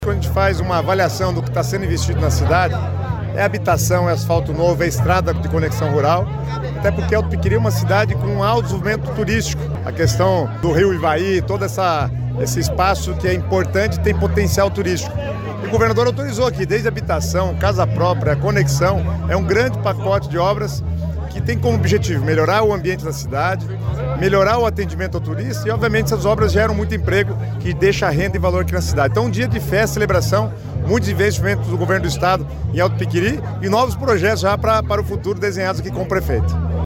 Sonora do secretário das Cidades, Guto Silva, pavimentação que vai facilitar escoamento agrícola em Alto Piquiri